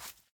Minecraft Version Minecraft Version snapshot Latest Release | Latest Snapshot snapshot / assets / minecraft / sounds / mob / turtle / baby / shamble1.ogg Compare With Compare With Latest Release | Latest Snapshot
shamble1.ogg